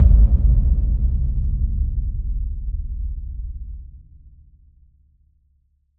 Low End 16.wav